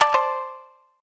shamisen_dac.ogg